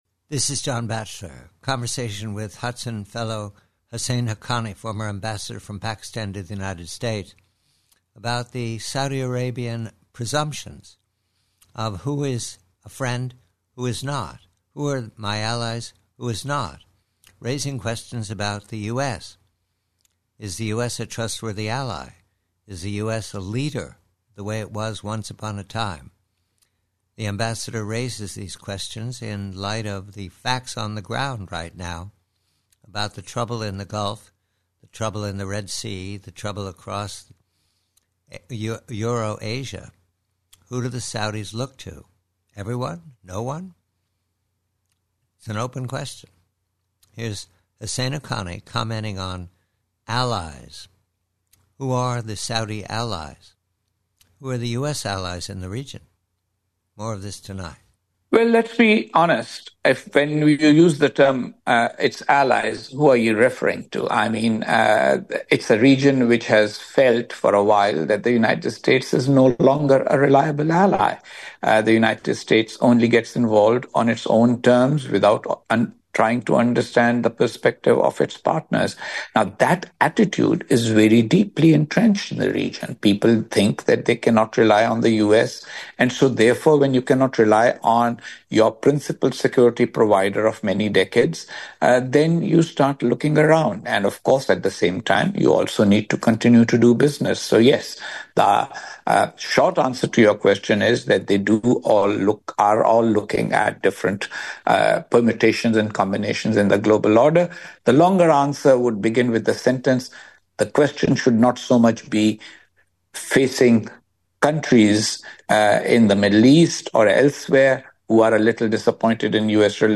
PREVIEW: GULF & KINGDOM: Conversation with colleague Husain Haqqani of Hudson Institute re the open question of who are Saudi Arabia's allies in the region and in the world -- and also the question of US intentions in the Gulf.